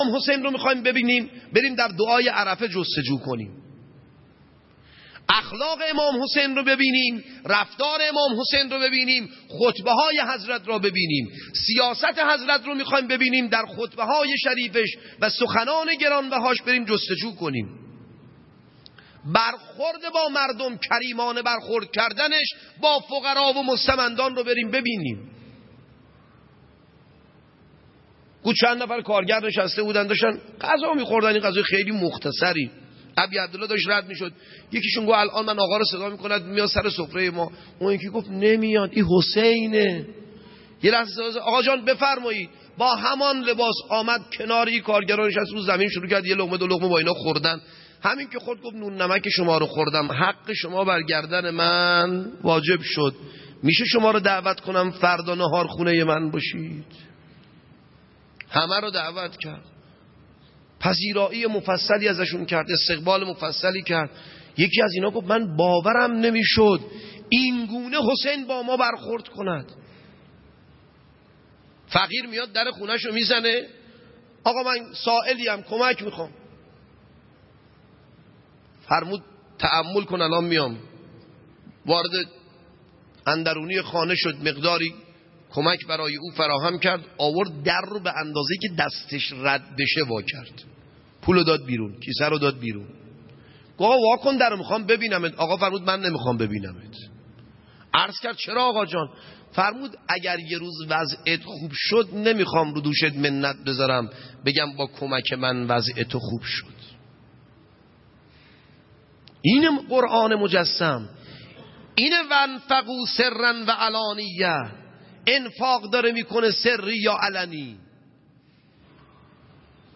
عقیق : مراسم عزاداری دهه دوم محرم با حضور جمع زیادی از عاشقان اهل بیت (ع) در هیئت آل یاسین برگزار شد.